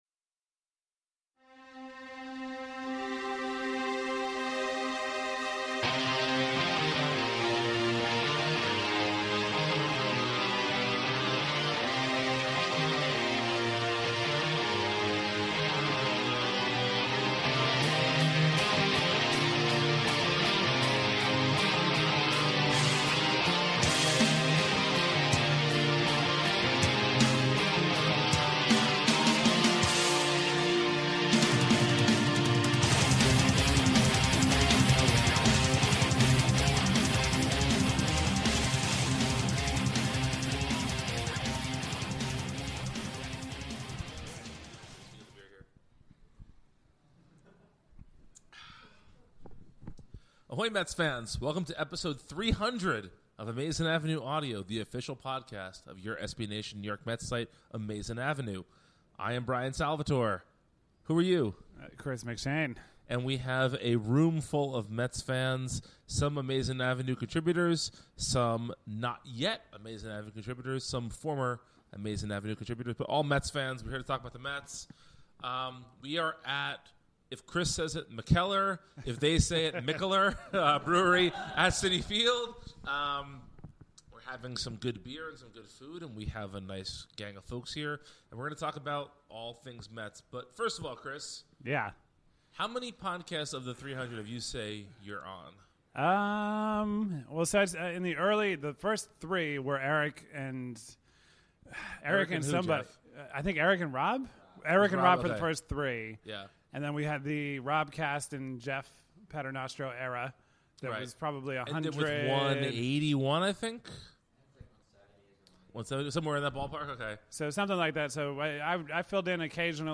Episode 300: Live at Mikkeller